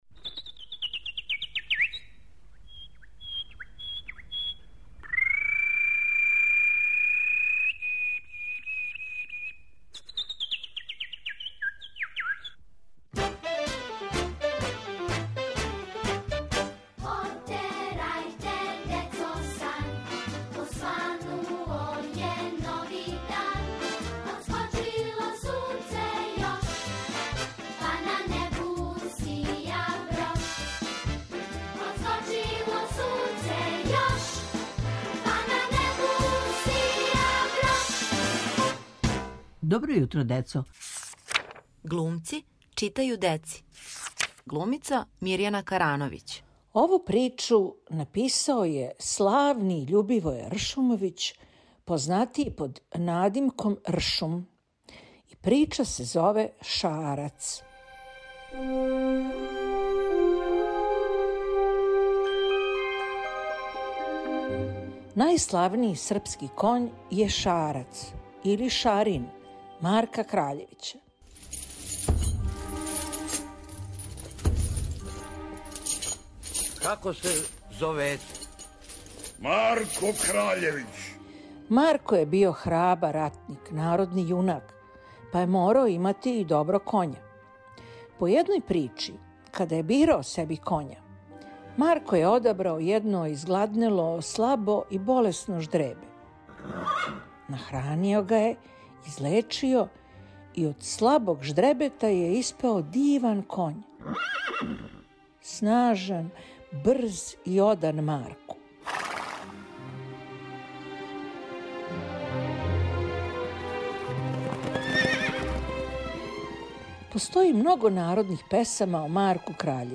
У новим околностима, када треба да останемо у својим кућама, представљамо вам потпуно нов серијал који се ствара баш у кући. Зове се "Глумци читају деци". Глумица Мирјана Карановић прочитаће причу Љубивоја Ршумовића.